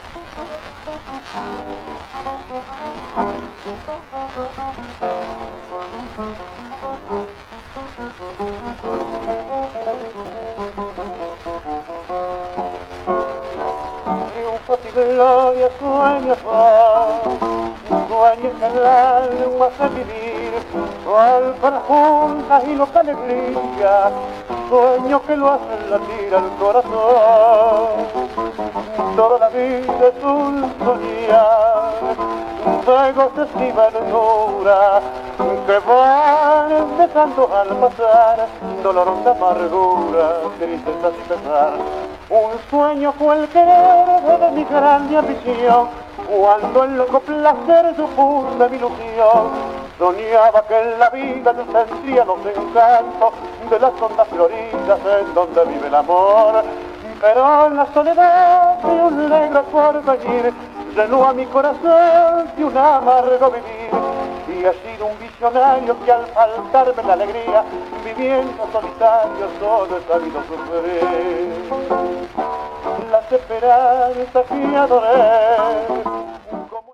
Tango
Musical
trio de guitarristes